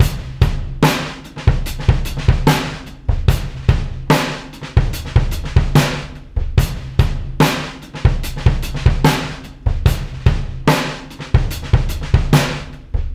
• 73 Bpm Breakbeat Sample D# Key.wav
Free breakbeat sample - kick tuned to the D# note. Loudest frequency: 778Hz
73-bpm-breakbeat-sample-d-sharp-key-epW.wav